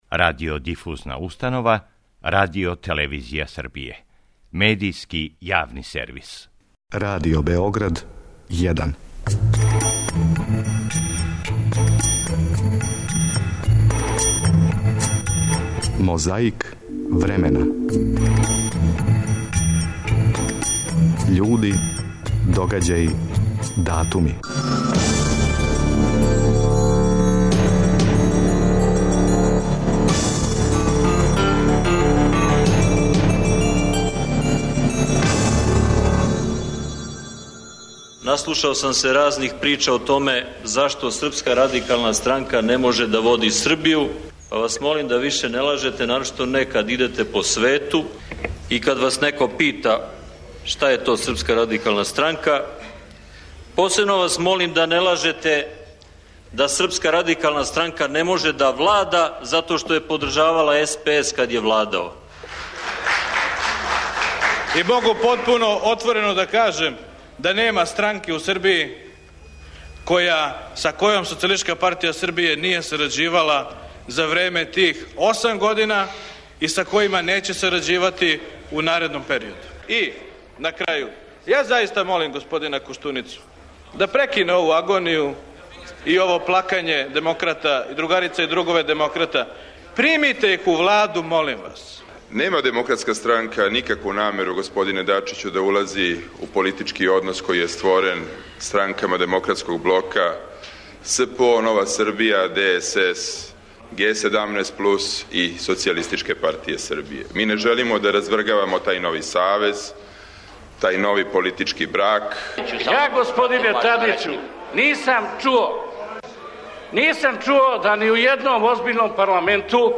На ванредној седници Скупштине СФРЈ одржаној 1. марта 1989. године расправљало се о одлуци председништва да се на Косову уведе ванредно стање. Том приликом говорио је члан председништва Лазар Мојсов.
О новонасталој ситуацији у студију Радио телевизије Сарајевио разговарали су Биљана Плавшић, Фрањо Борас и Велибор Остојић.